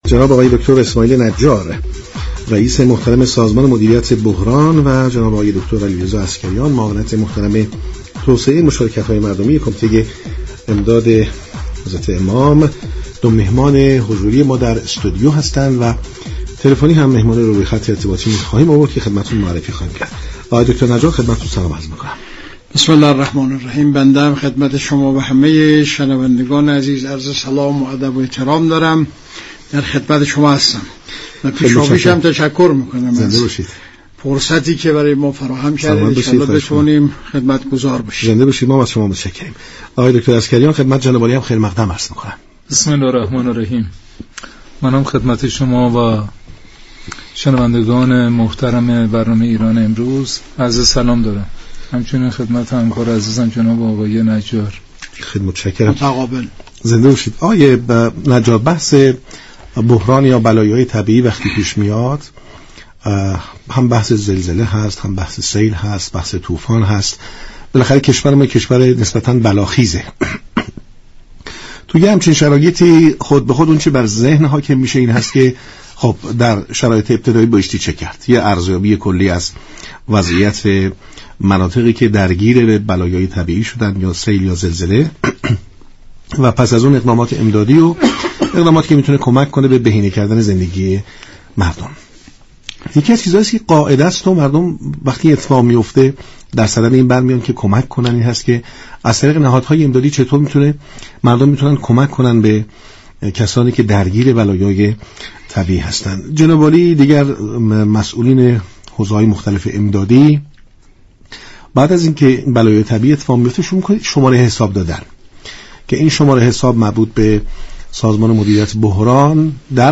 رییس سازمان مدیریت بحران در گفت و گو با رادیو ایران گفت: در بحث كمك رسانی ها ممكن است به صورت جزئی كوتاهی هایی صورت گیرد اما نباید آن را به كل تعمیم داد.